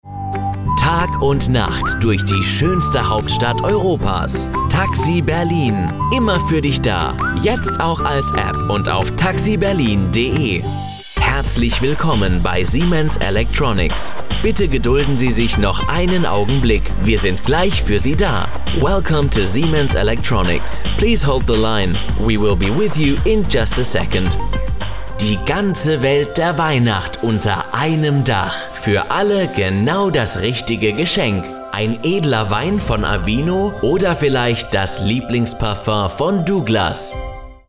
Native German Voice - Deutscher Sprecher
Kein Dialekt
Sprechprobe: eLearning (Muttersprache):